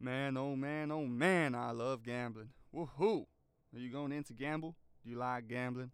Voice Lines
man oh man OH MAN i love gambling.wav